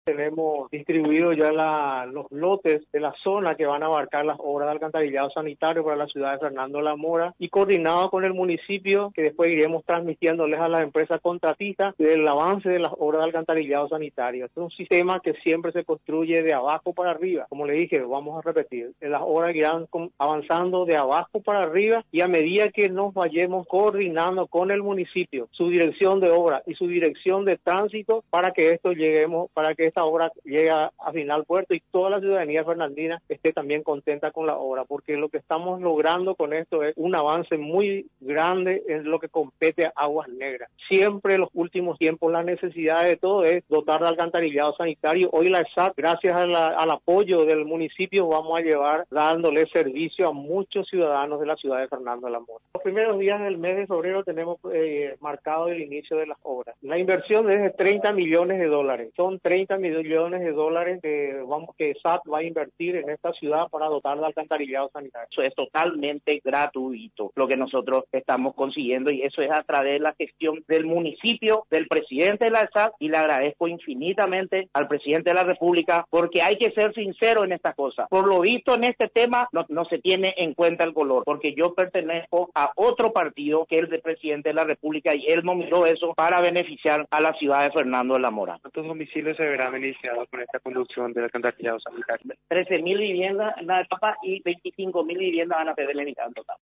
Este sistema de alcantarillado beneficiará a unas veinticinco mil viviendas, informó este lunes el intendente Alcides Riveros, luego de la reunión mantenida con el presidente de la Essap, y otras autoridades.
33-ALCIDES-RIVEROS-intendente-de-Fdo-de-la-Mora-entre-otros.mp3